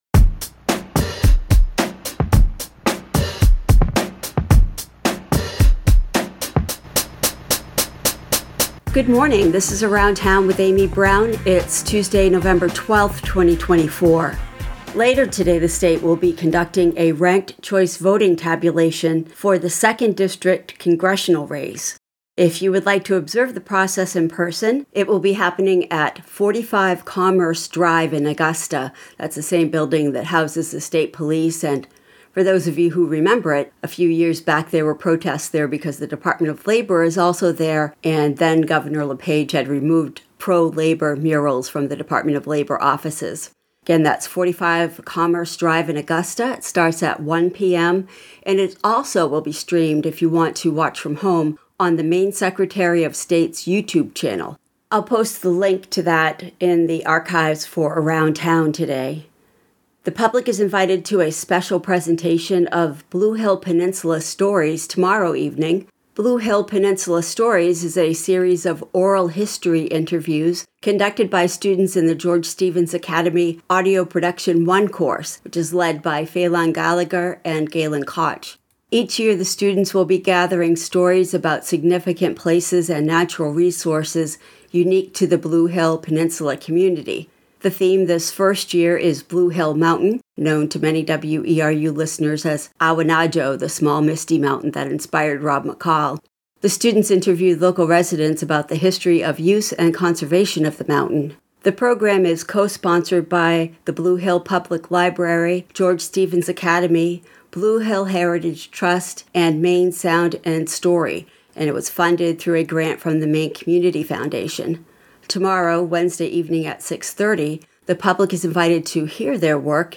Local news & events